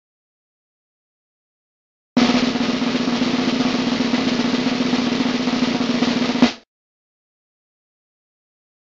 جلوه های صوتی
دانلود صدای تبل 2 از ساعد نیوز با لینک مستقیم و کیفیت بالا